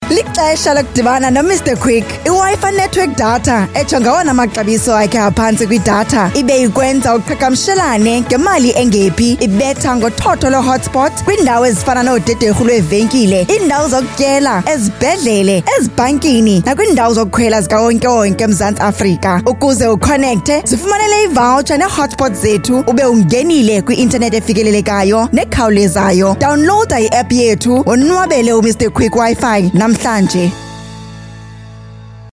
articulate, authoritative, bright, commercial, confident, conversational, energetic, informative
Her voice has a unique or rare natural warmth, that can capture the attention of the audience.
Hard Sell